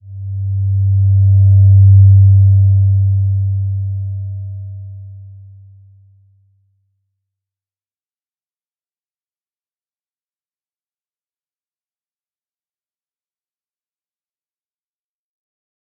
Slow-Distant-Chime-G2-p.wav